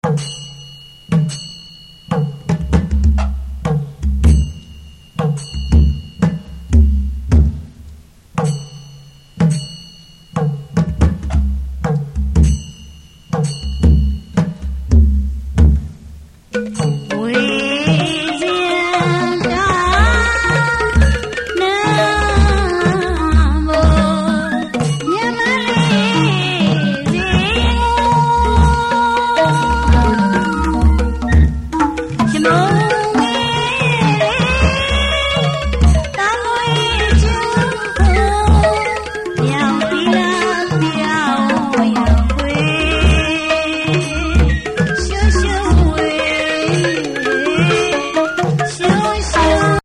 The mysterious and flamboyant Burmese drum and gong ensemble, rarely heard outside Burma (and is increasingly rare within Burma as well).
Although these instruments are somewhat reminiscent of some traditional Thai or Indonesian gong ensembles, this seems almost reckless, sometimes on the verge of falling apart, but suddenly coming together, only to fall into chaos yet again.
Each of the 21 or 22 drums are tuned by adding or removing the paste located at the center of each drum head.
Note:  This music is from a cassette we purchased after watching the marionette show.
As it's tied to the action onstage, the music never stays at the same tempo for long. Occasionally, a reverby vocal will enter the mix.